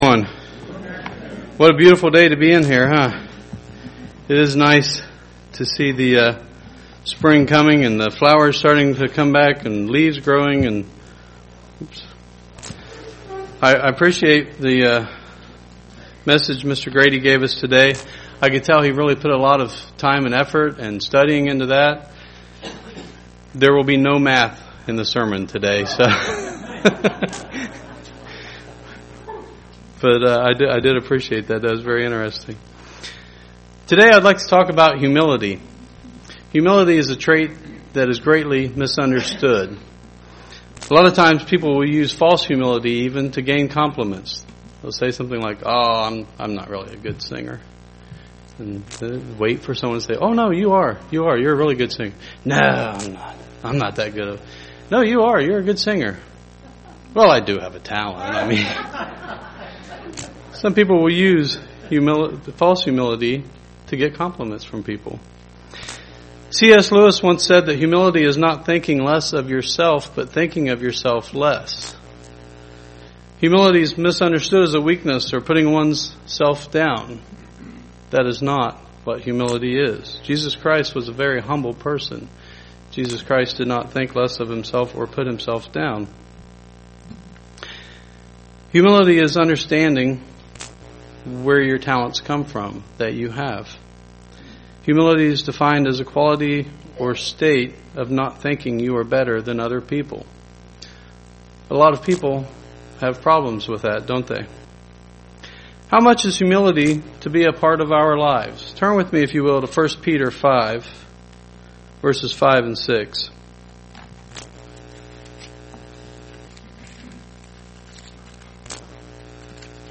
Listen to this sermon to discover what it means to be a humble person and learn some characteristics that a humble person possesses.
Given in Dayton, OH